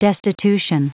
Prononciation du mot destitution en anglais (fichier audio)
Vous êtes ici : Cours d'anglais > Outils | Audio/Vidéo > Lire un mot à haute voix > Lire le mot destitution